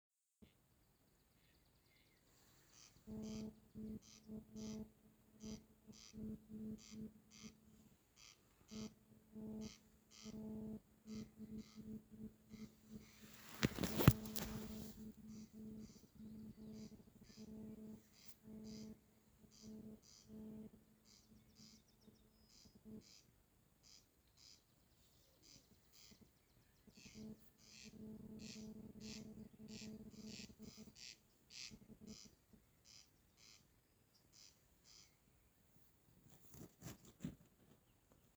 коростель, Crex crex
Skaits3 - 4
СтатусПоёт